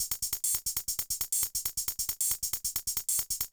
drums04.wav